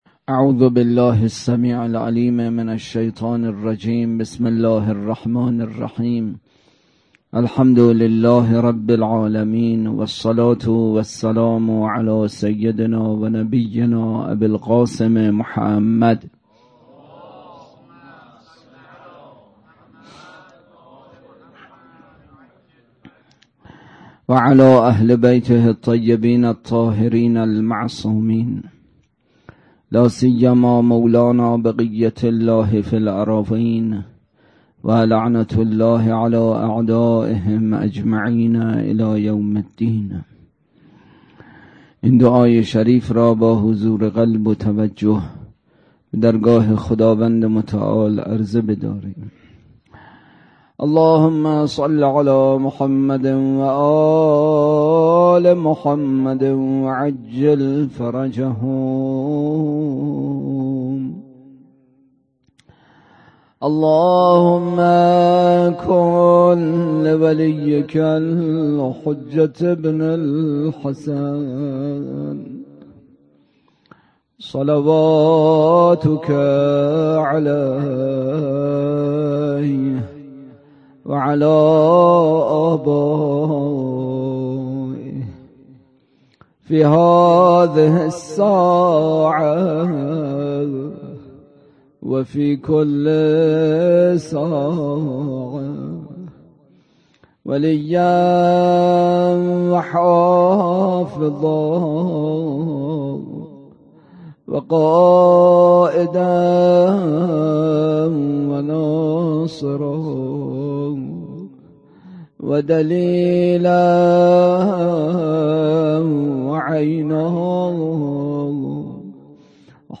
سخنرانی
برگزارکننده: هیئت سفینه النجاه
روضه: حضرت علی اصغر(ع)